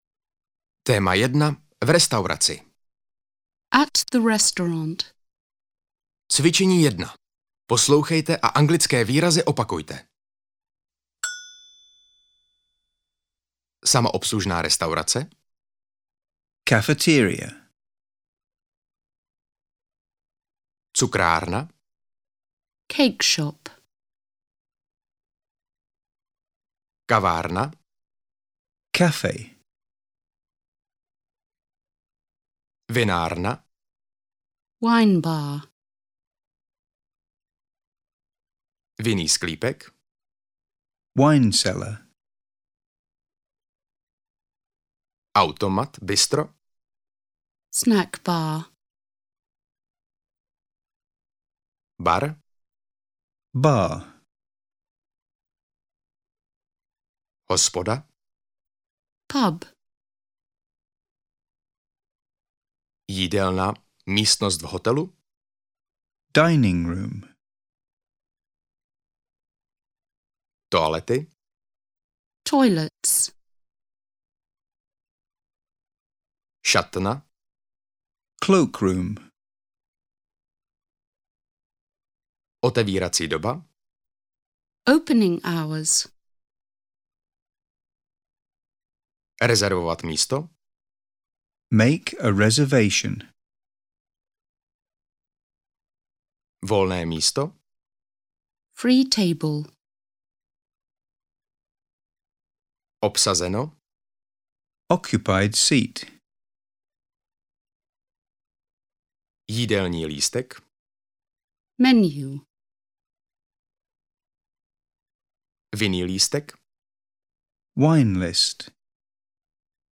Ukázka z knihy
Audiokniha Anglicky bez učebnice - Restaurace a jídlo obsahuje nahrávky v anglickém jazyce s českým komentářem, různorodá témata a cvičení včetně opakování slovíček a správné výslovnosti.Chcete si rozšířit svou slovní zásobu?
• InterpretRôzni Interpreti